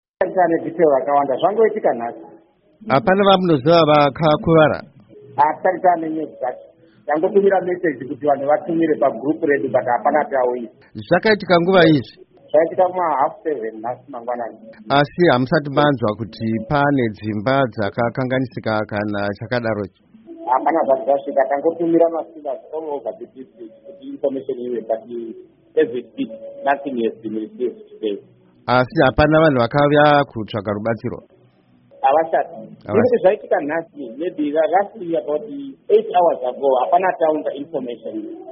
Hurukuro naVaWilliam Mashava